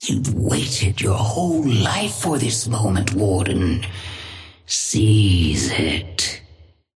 Sapphire Flame voice line - You've waited your whole life for this moment, Warden. Seize it.
Patron_female_ally_warden_start_03.mp3